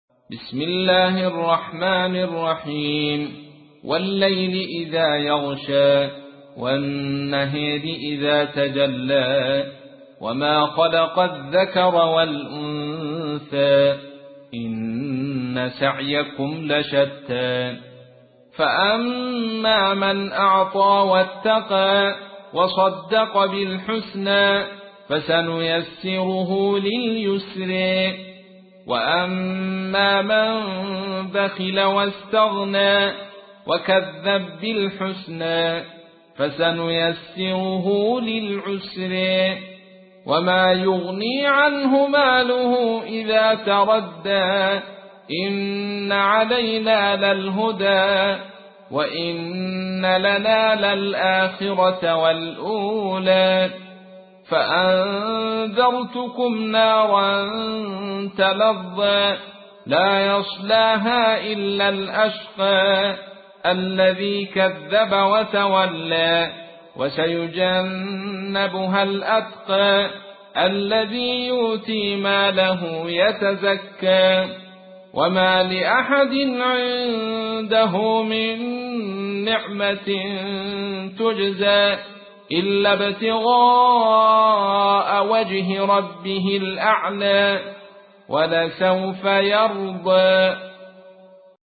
تحميل : 92. سورة الليل / القارئ عبد الرشيد صوفي / القرآن الكريم / موقع يا حسين